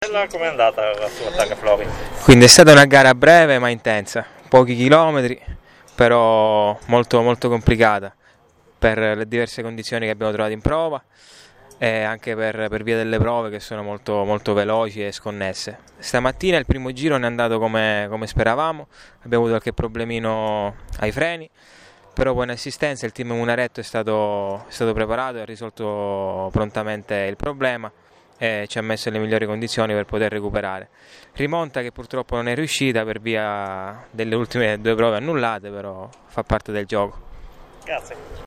Interviste Rally Targa Florio 2020
Interviste di fine rally